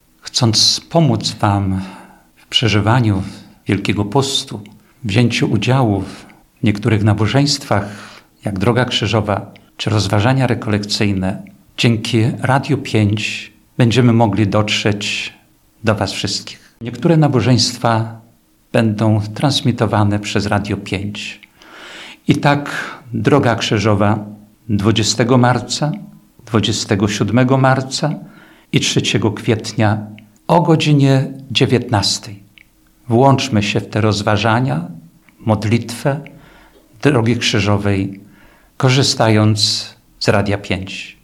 Mówi ksiądz biskup Jerzy Mazur, ordynariusz diecezji ełckiej: